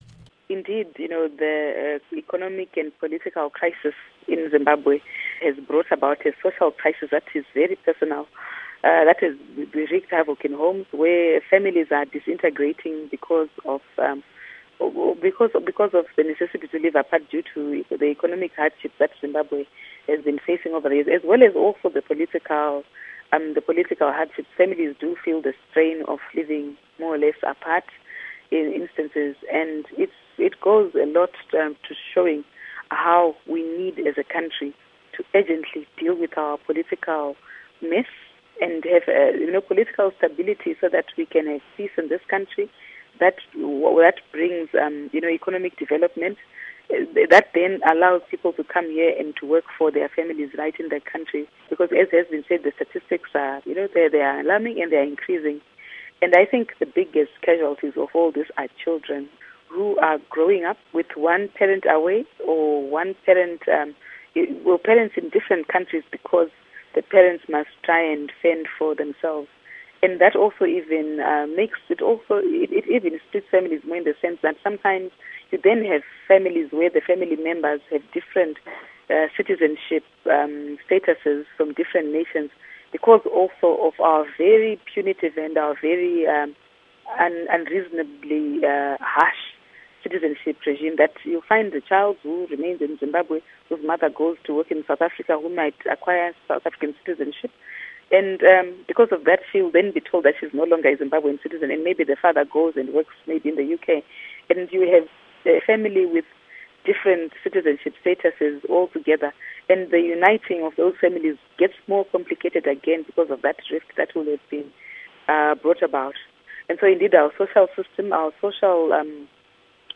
Interview with Jessie Majome